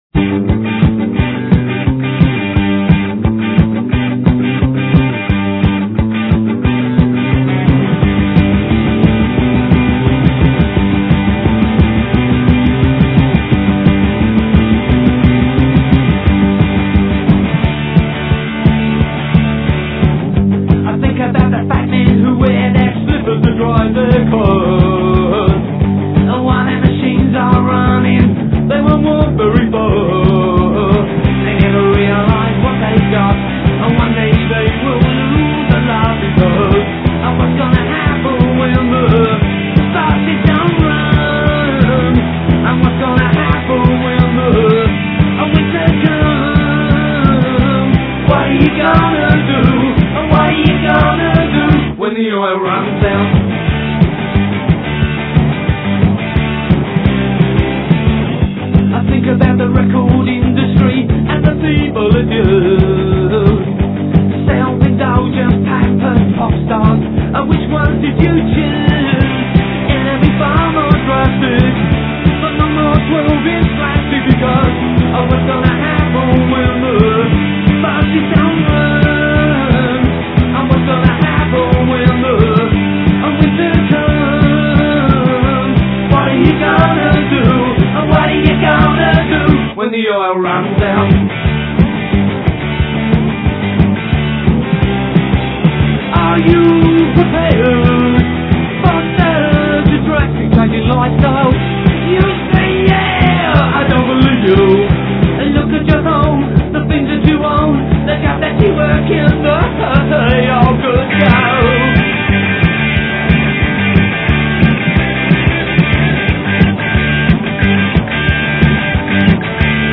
(Low quality full version)